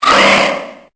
Cri de Skelénox dans Pokémon Épée et Bouclier.